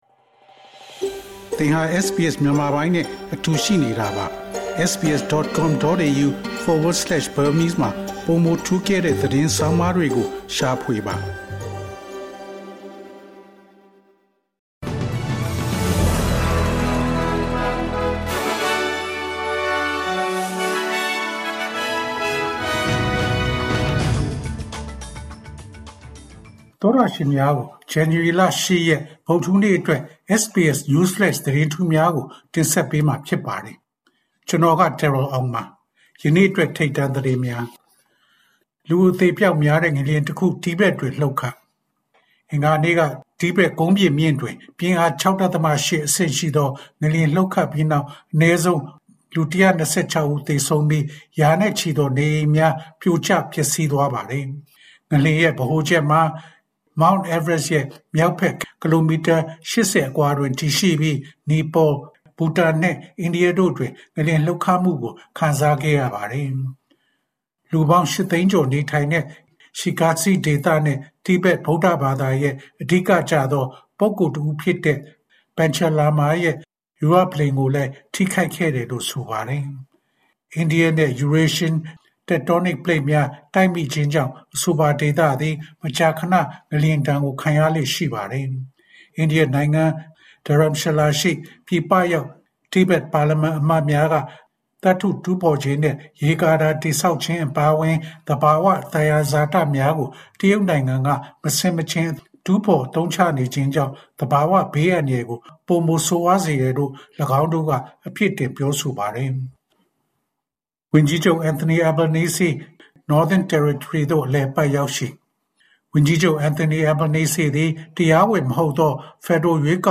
ALC: January ၈ ရက် ၂၀၂၅ ခုနှစ်, SBS Burmese News Flash.